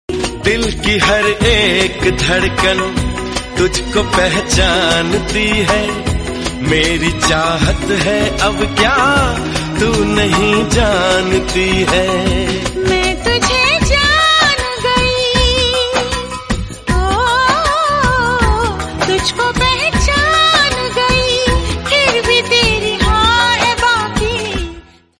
Bollywood 4K Romantic Song